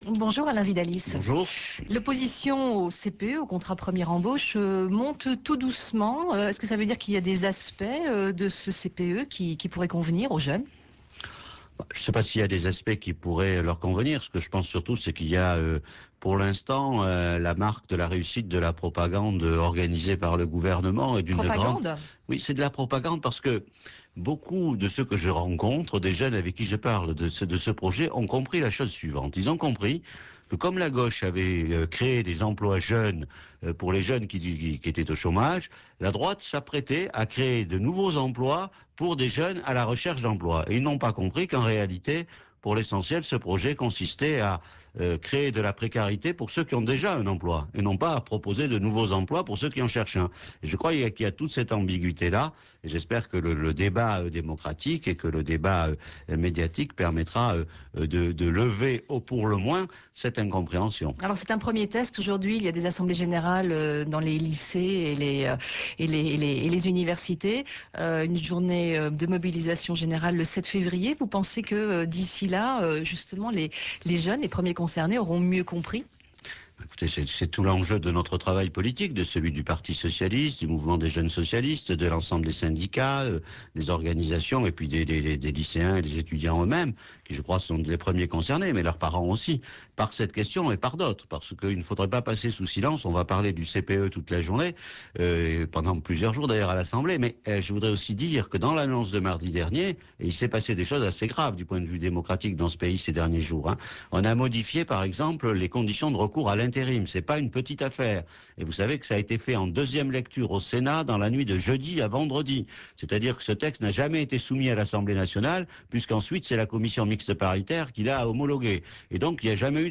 Alain VIDALIES était "l'invité du matin" sur RFI le lundi 31 janvier à 8heures 15.